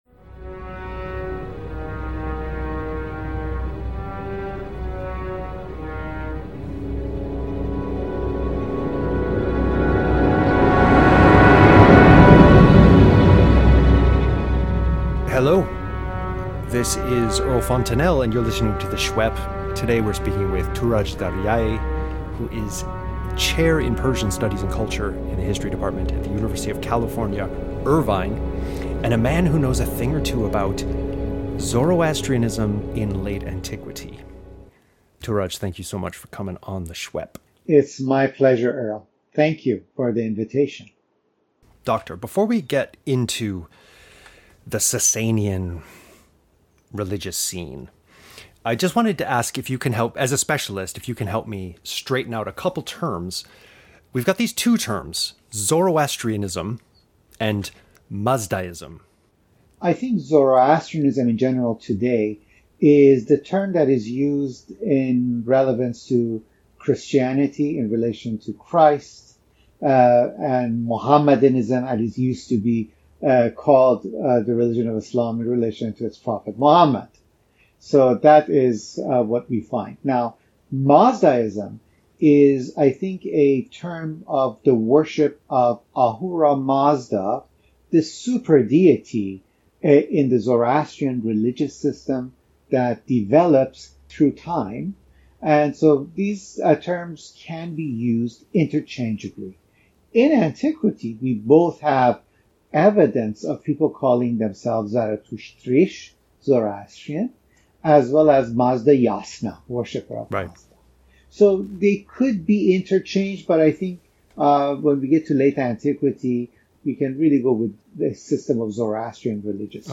Interview Bio